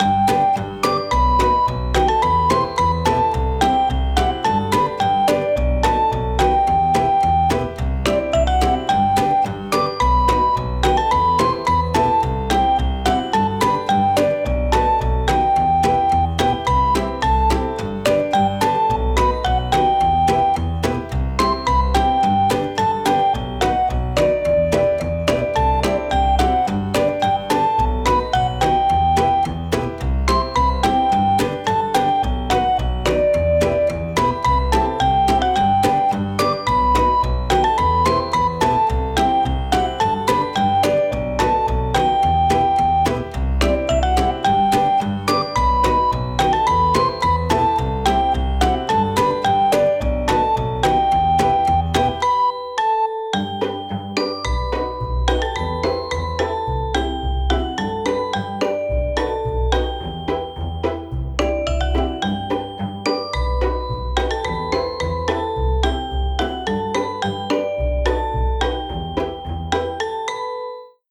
ほのぼの・日常
【ループ版あり】ほのぼのとした日常系のゆったりBGMです
軽やかで明るくかわいい印象に仕上げました◎